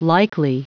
Prononciation du mot likely en anglais (fichier audio)
Prononciation du mot : likely